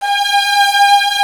Index of /90_sSampleCDs/Roland L-CD702/VOL-1/CMB_Combos 1/CMB_Brite Strngs